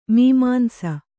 ♪ mīmāmsa